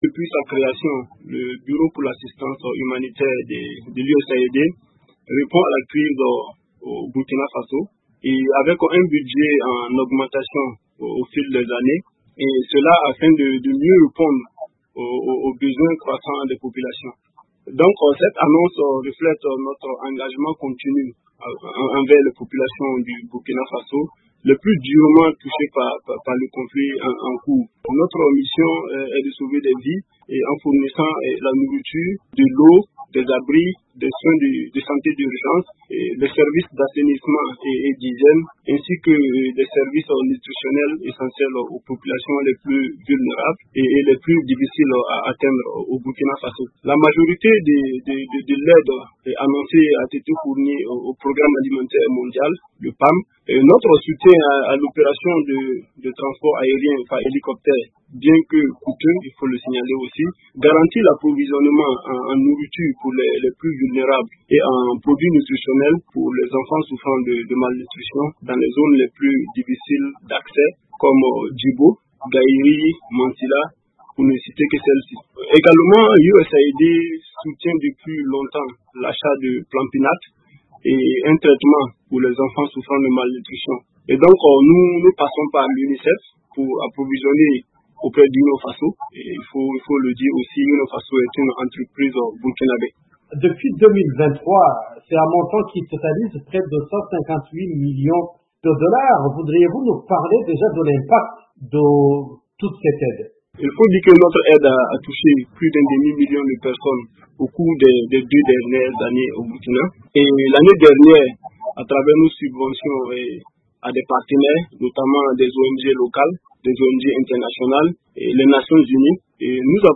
Près de 3 millions de Burkinabè vont être confrontés à une insécurité alimentaire, nécessitant une aide d’urgence. Pour en parler, VOA a joint à Ouagadougou,